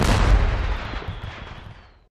firework_explosion_02.ogg